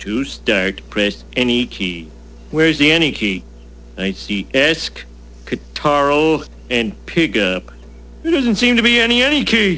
Homer Simpson typing on a computer